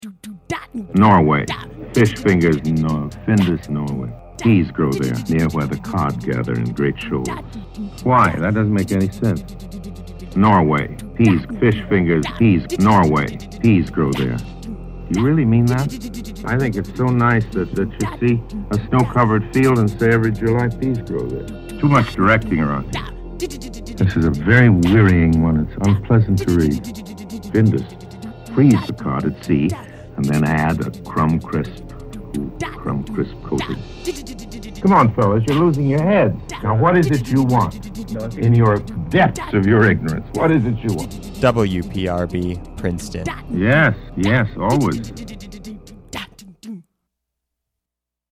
providing a non-verbal impression of the drum fill from Big Star’s “September Gurls” in WPRB’s infamous “Fish Fingers” station ID.
Fish_Fingers_Station_ID.mp3